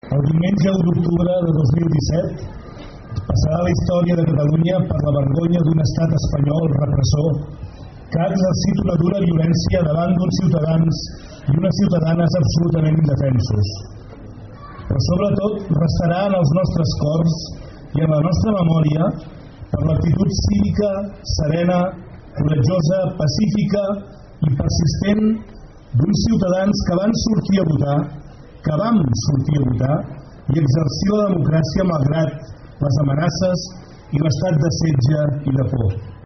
Concentració de rebuig a la violència policial, ahir davant l'Ajuntament de PLF - Foto: Ràdio PLF
Al final de l’acte va llegir-se un manifest, en el qual es va condemnar la violència de l’Estat espanyol a través de la repressió aplicada a les persones que havien anat als col·legis.